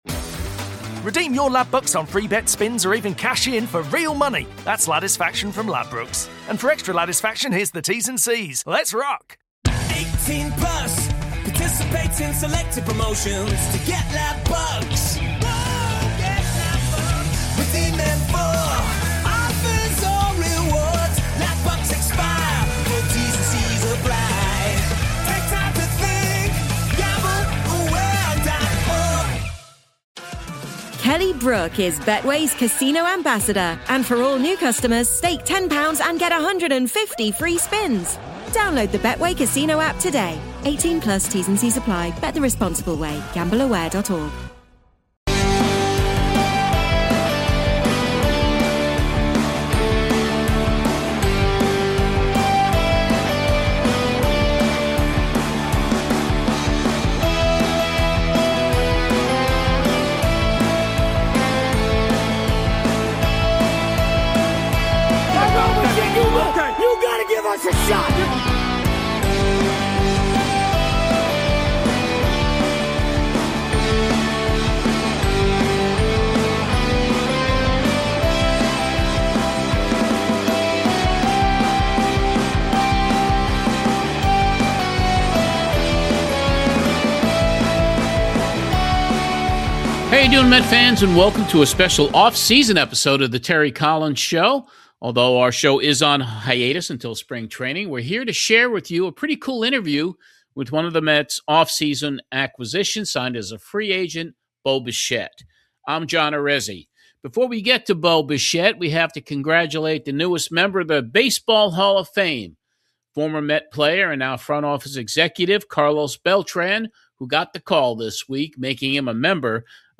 BONUS EPISODE. In this bonus episode of The Terry Collins Show, we feature a very special conversation with the newest Mets free agent acquisition, BO BICHETTE.